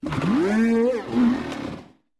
Cri de Motorizard dans Pokémon Écarlate et Violet.